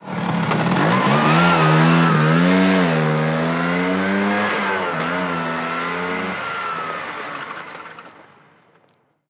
Moto marca Vespa arrancando y saliendo
moto
Sonidos: Transportes